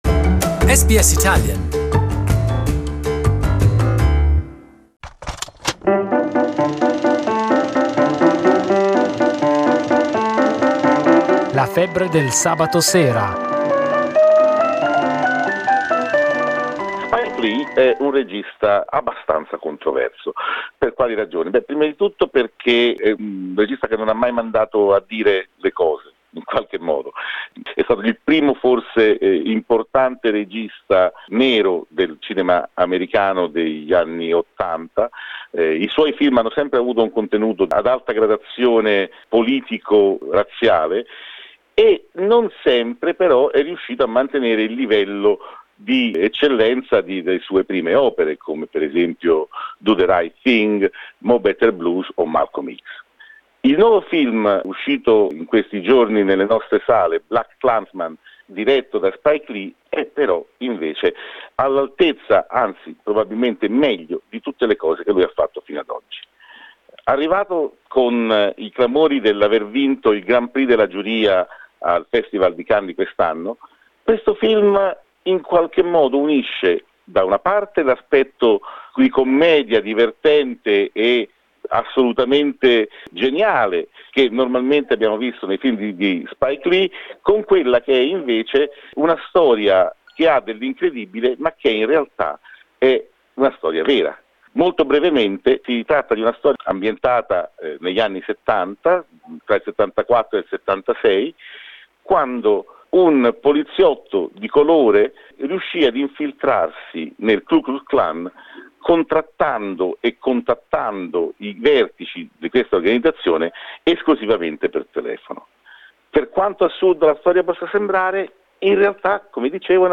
BlacKkKlansman is a 2018 American biographical comedy-drama film directed by Spike Lee. A review